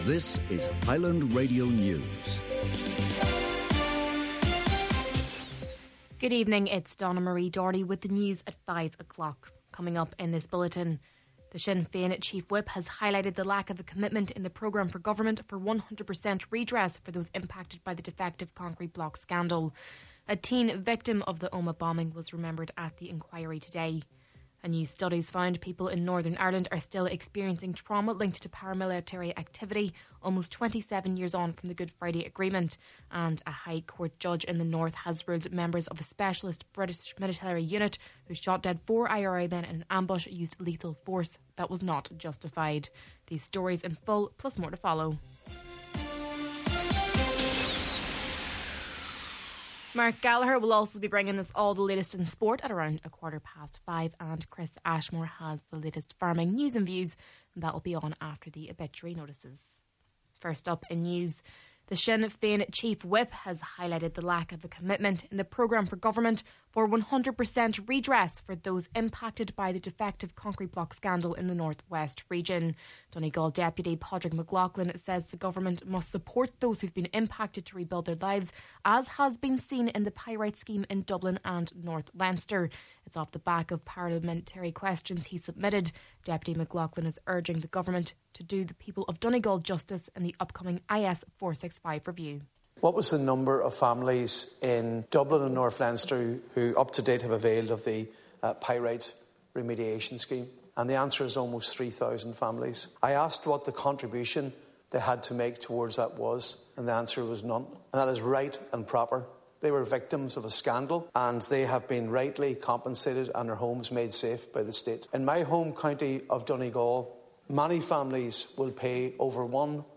Main Evening News, Sport, Farming News and Obituaries – Thursday, February 6th